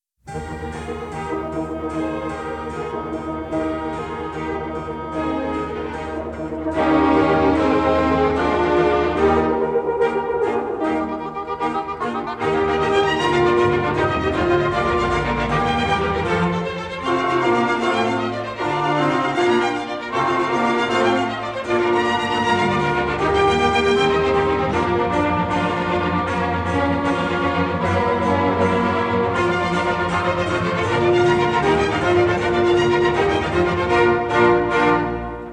in E flat major